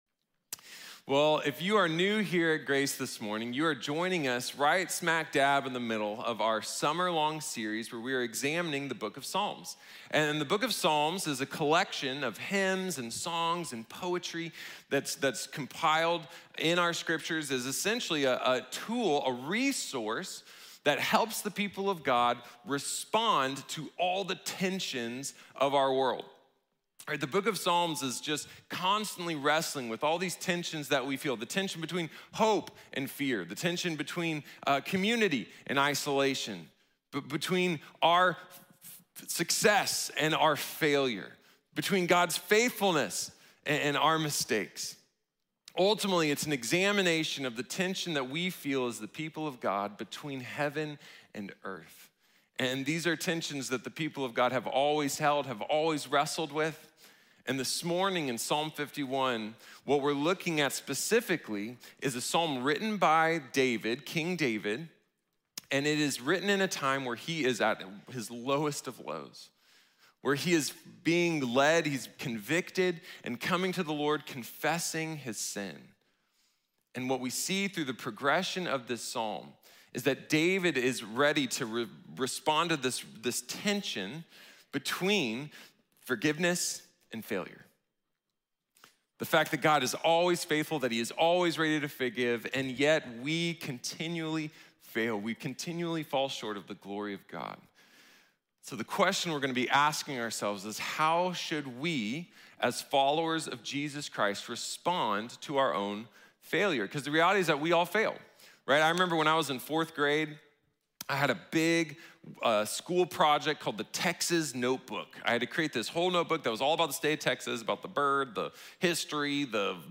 God Forgives | Sermon | Grace Bible Church